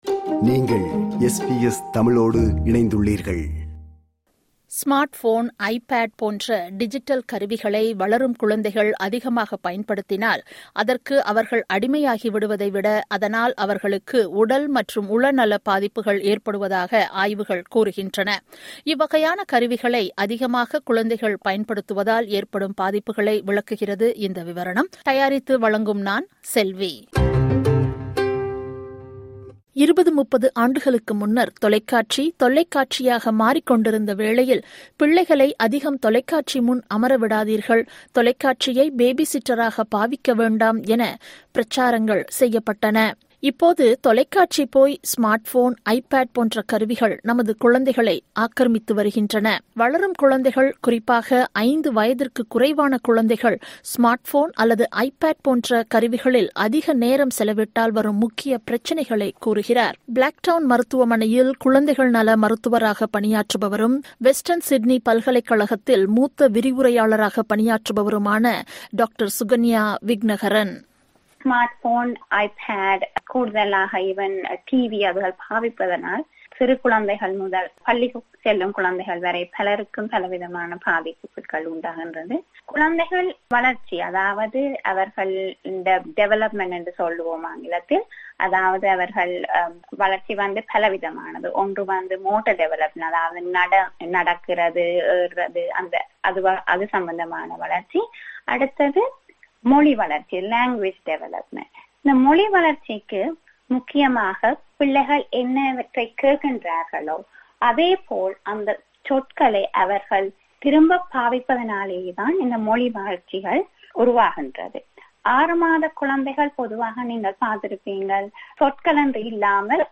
Smartphone, Ipad போன்ற டிஜிட்டல் கருவிகளை வளரும் குழந்தைகள் அதிகமாக பயன்படுத்தினால் அதற்கு அவர்கள் அடிமையாகி விடுவதைவிட அதனால் அவர்களுக்கு உடல் மற்றும் உள நல பாதிப்புகள் ஏற்படுவதாக ஆய்வுகள் கூறுகின்றன. இவ்வகையான கருவிகளை அதிகமாக குழந்தைகள் பயன்படுத்துவதால் ஏற்படும் பாதிப்புகளை விளக்குகிறது இந்த விவரணம்.